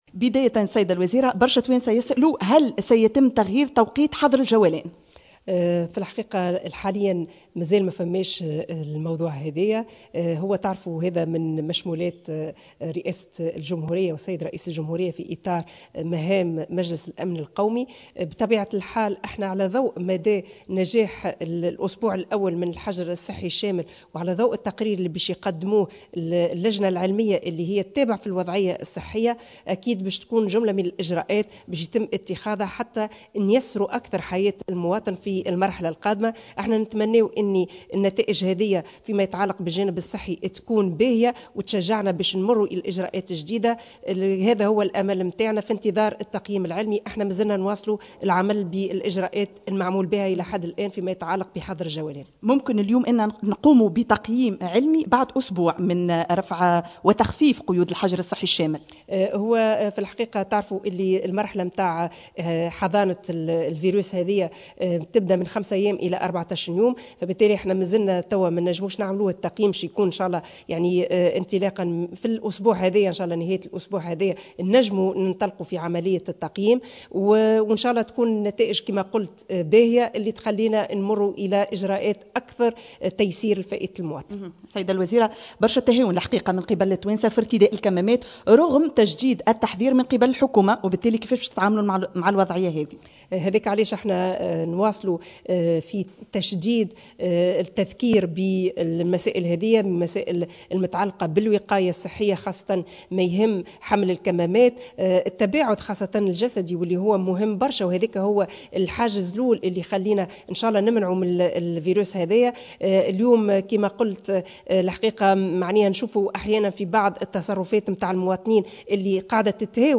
الناطقة الرسمية باسم الحكومة أسماء السحيري